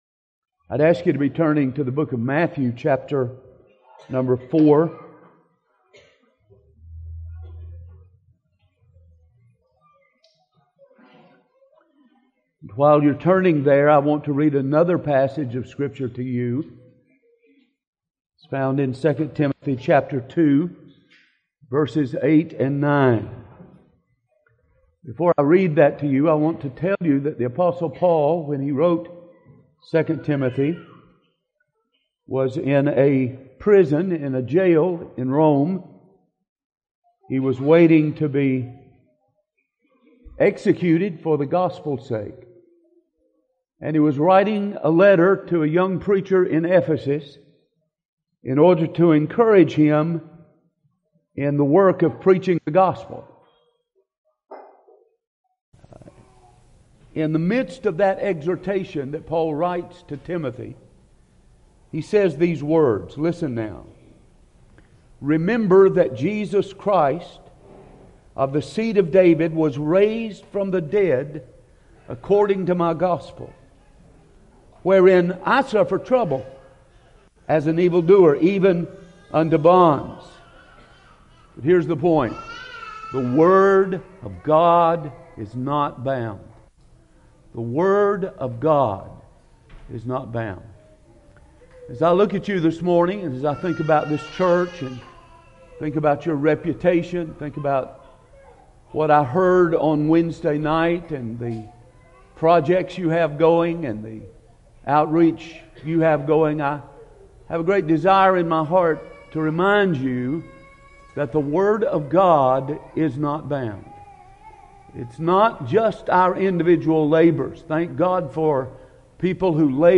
2015 Category: Full Sermons